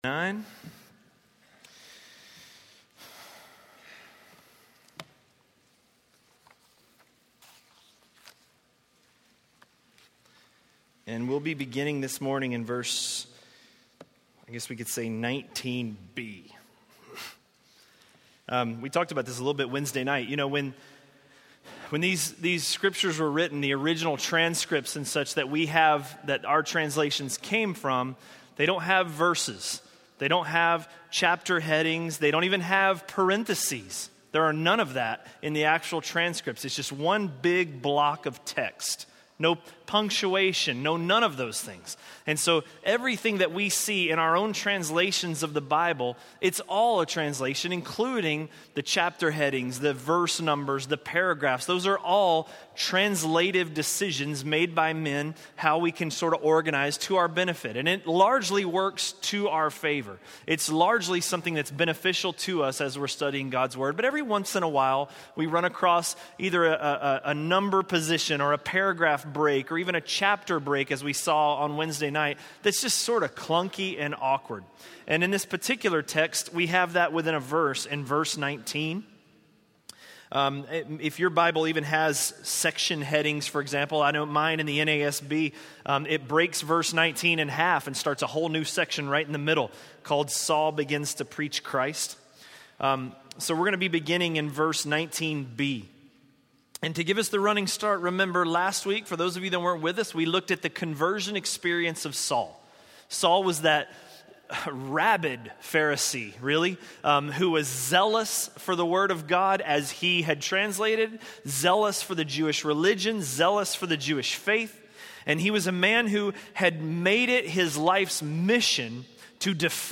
A message from the series "(Untitled Series)." by